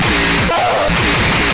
描述：另一个糟糕的grindcore /死亡金属轨道。没有人声。
标签： 金属 死亡 碾核
声道立体声